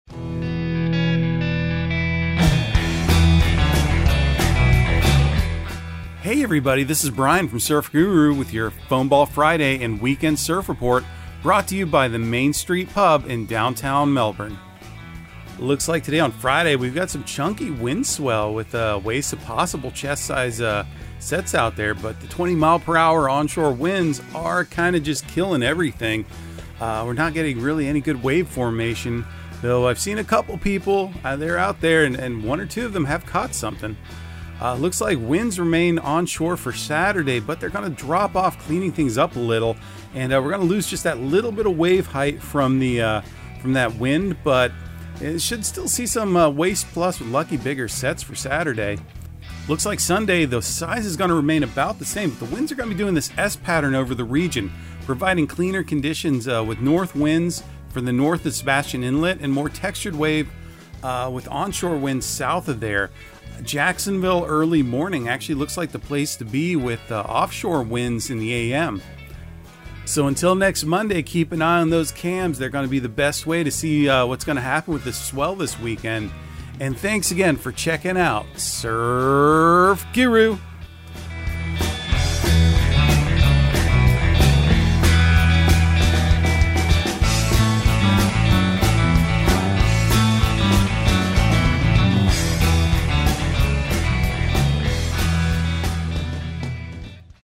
Surf Guru Surf Report and Forecast 12/02/2022 Audio surf report and surf forecast on December 02 for Central Florida and the Southeast.